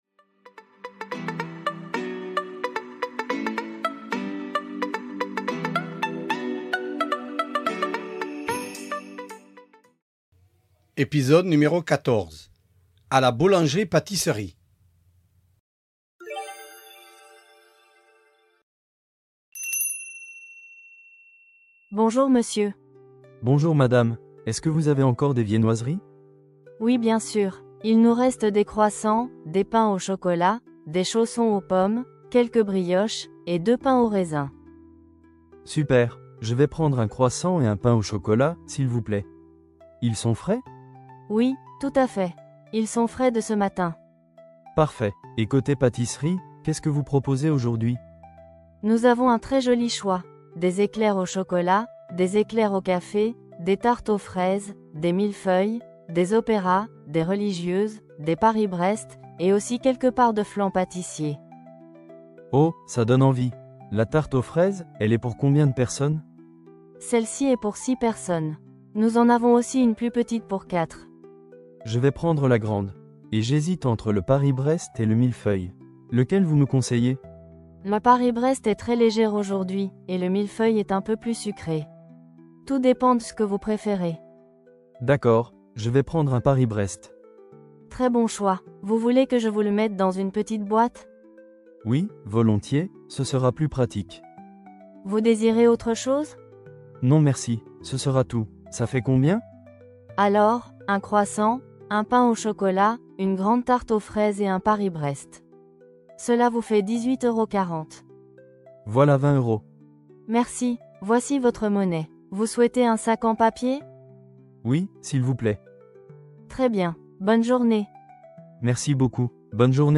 Voici un dialogue pour les élèves de niveau débutant. Avec cet épisode, vous verrez le vocabulaire des pâtisseries et des viennoiseries.
014-Podcast-dialogues-A-la-boulangerie-patisserie.mp3